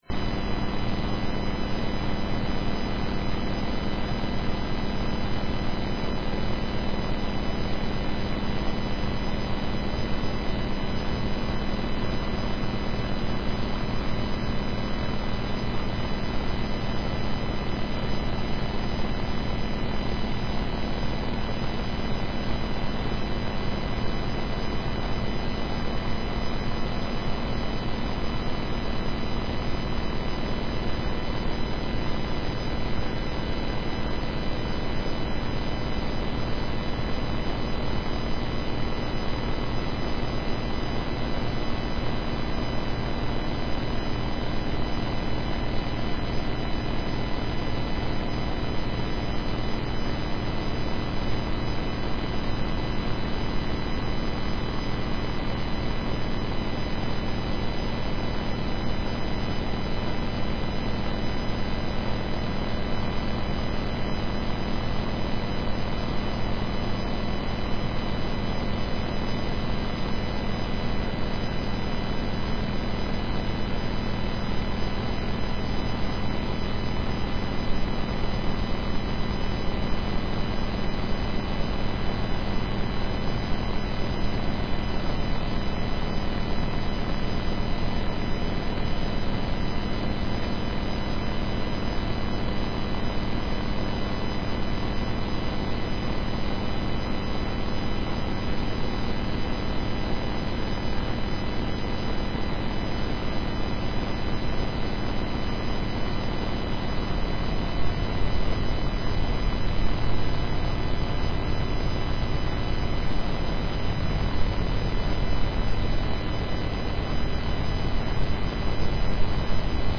Last Sunday Morning Service
sundaymorning.mp3